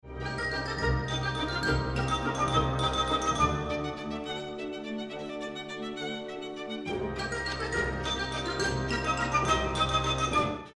During one of the recipes that require stirring, try making the work a little more fun by using motions that reflect two contrasting musical styles: legato (pronounced luh-GAHT-toh) and staccato (pronounced stah-CAHT-toh).
Use short and separated strokes while stirring, like the staccato music.
Fam1-Activity_Staccato.mp3